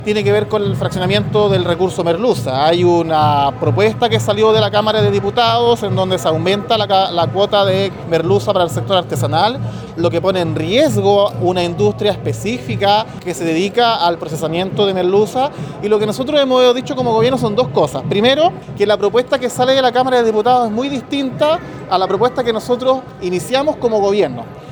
El seremi de Economía del Bío Bío, Javier Sepúlveda, reconoció que lo que se despachó al Senado desde la Cámara de Diputados no está acorde a lo presentado por el Gobierno. También reconoció que el fraccionamiento de la merluza puede causar problemas en sectores de la industria pesquera.